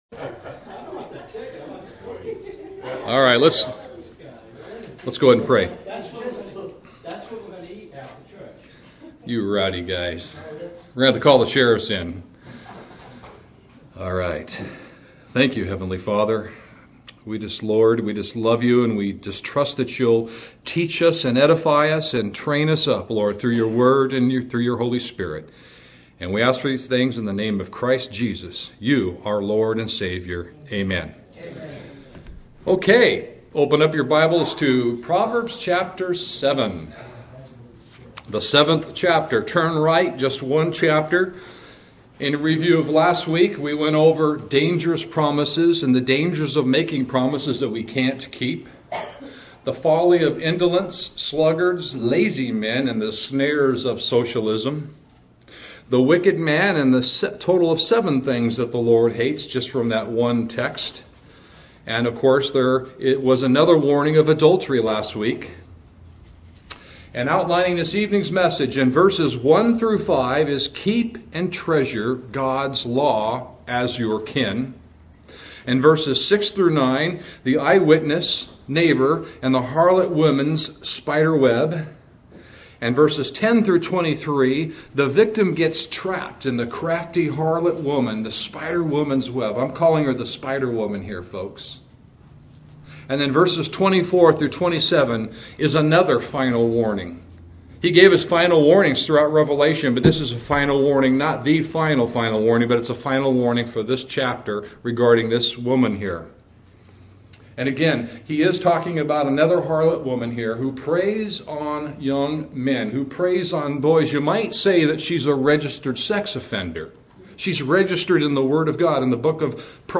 PROVERBS Chap 7:18 My boyfriend cheated on me & how Satan or an unbiblical love would respond to her (sermon jam)